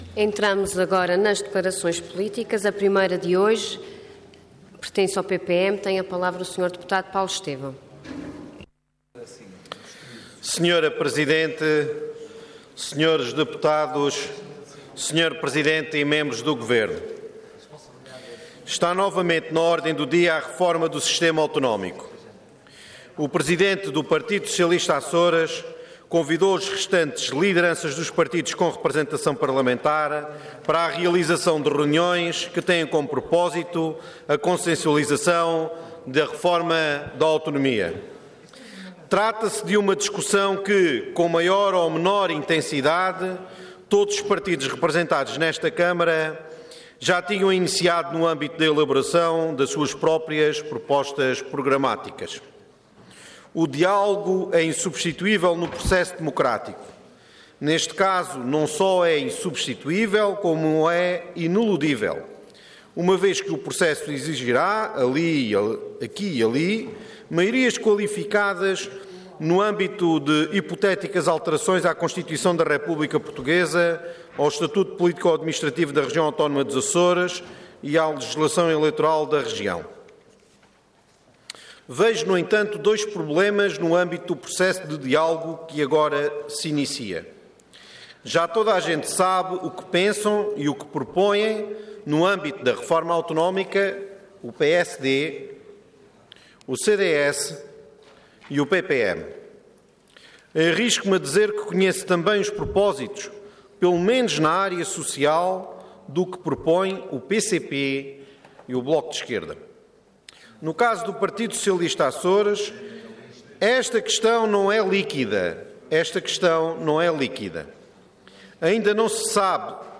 Detalhe de vídeo 13 de janeiro de 2016 Download áudio Download vídeo Processo X Legislatura A reforma da autonomia Intervenção Declaração Política Orador Paulo Estêvão Cargo Deputado Entidade PPM